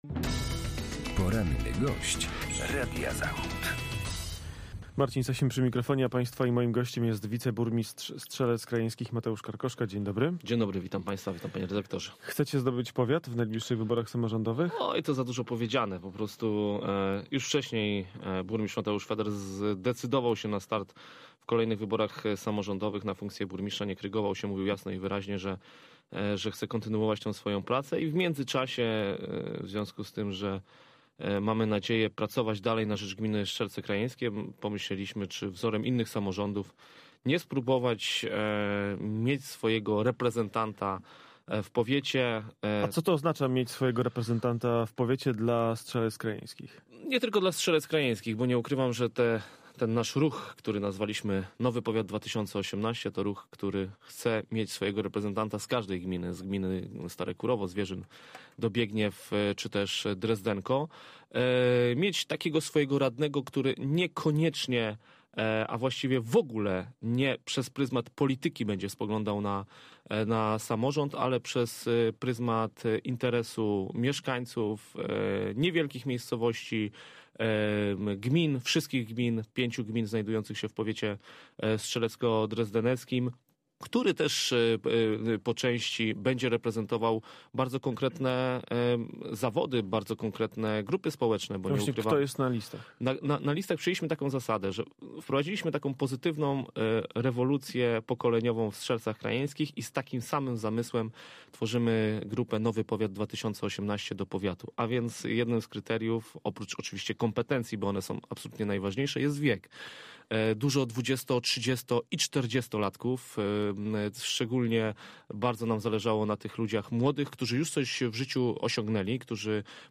Wczoraj gościem Radia Zachód był Zastępca Burmistrza Strzelec Krajeńskich - Mateusz Karkoszka . Opowiadał o nadchodzących wyborach i chęci powalczenia o radnych powiatowych w nadchodzących wyborach.